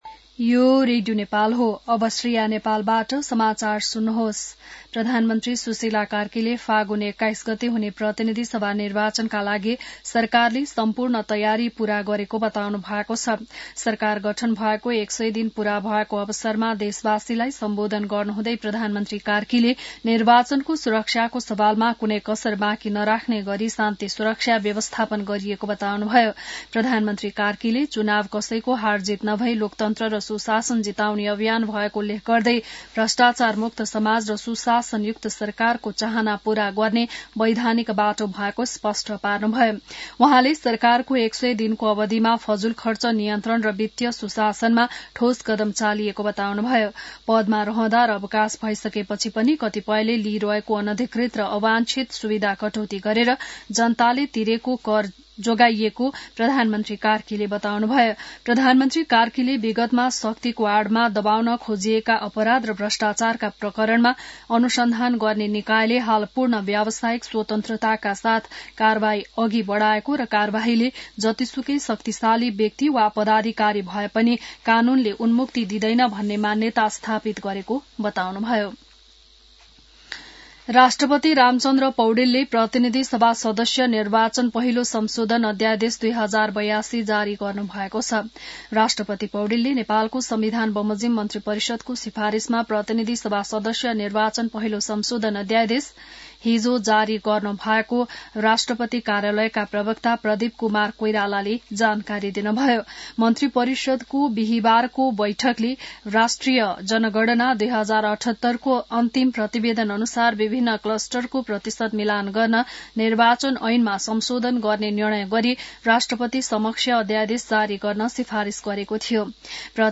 बिहान १० बजेको नेपाली समाचार : ५ पुष , २०८२